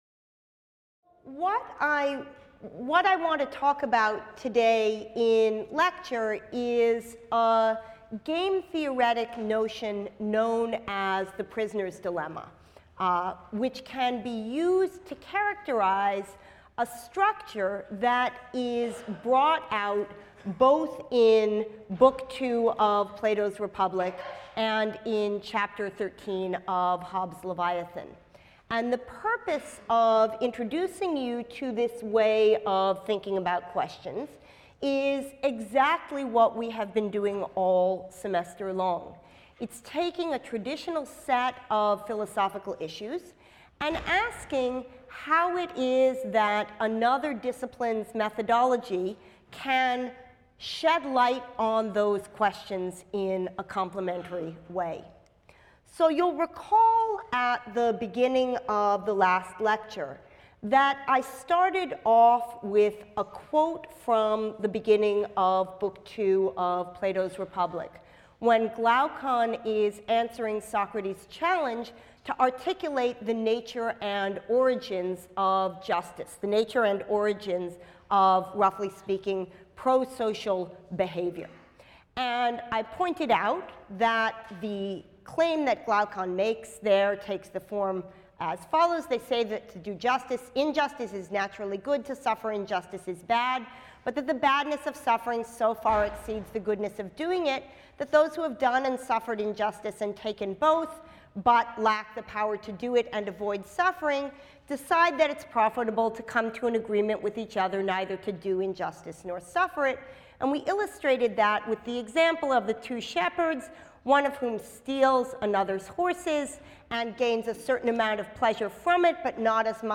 PHIL 181 - Lecture 20 - The Prisoner’s Dilemma | Open Yale Courses